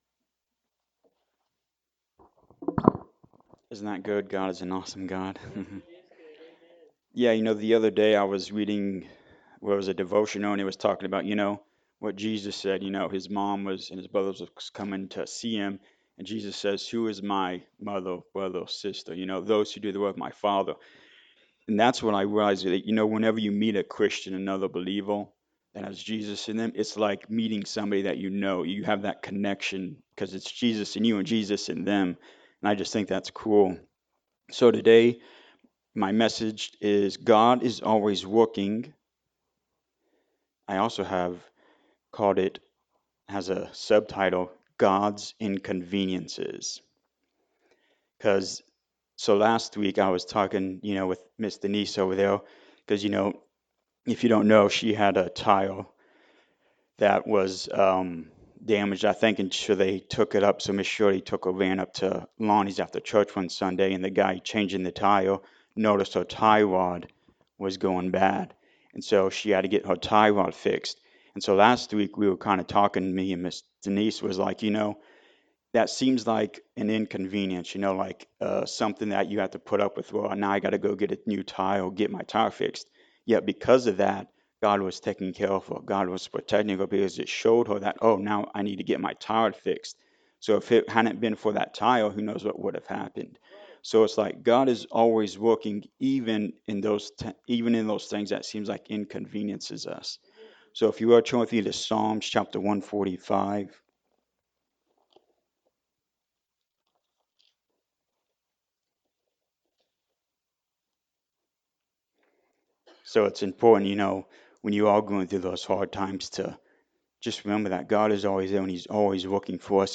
Romans 5:1-5 Service Type: Sunday Morning Service Do you know that God loves you?